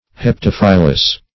Search Result for " heptaphyllous" : The Collaborative International Dictionary of English v.0.48: Heptaphyllous \Hep*taph"yl*lous\, a. [Hepta- + Gr.